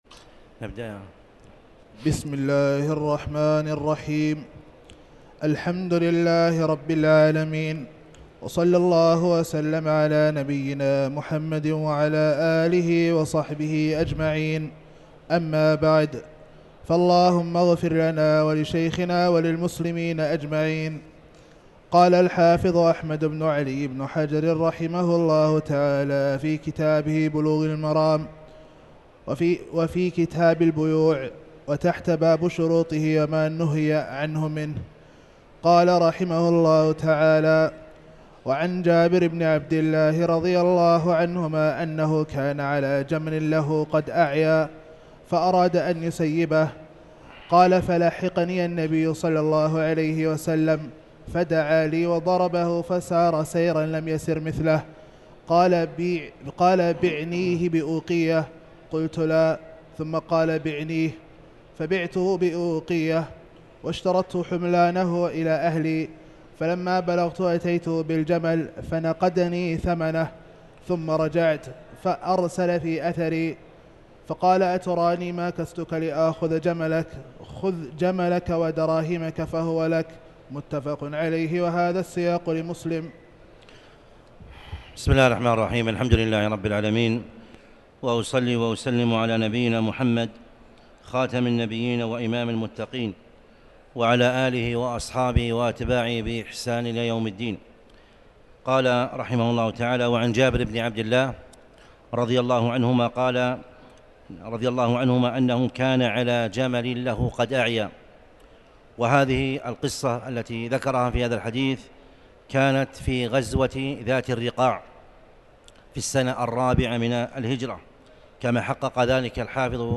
تاريخ النشر ٢٤ محرم ١٤٤٠ هـ المكان: المسجد الحرام الشيخ